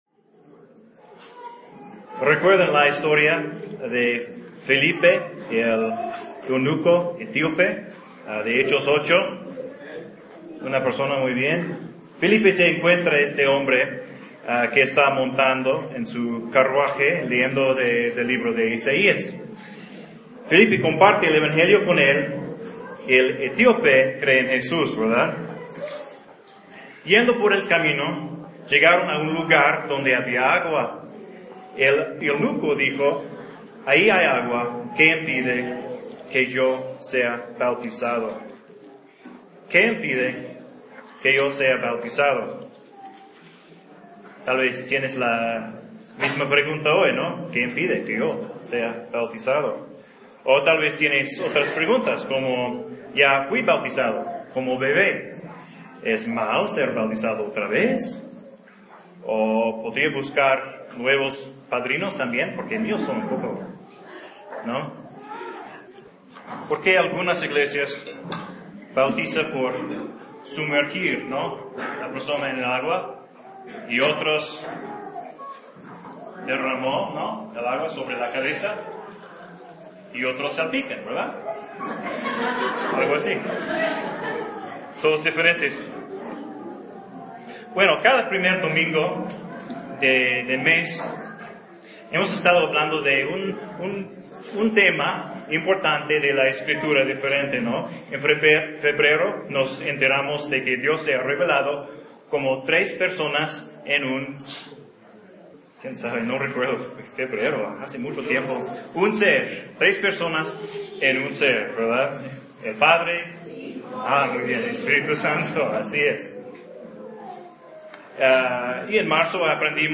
El Bautismo (sermón)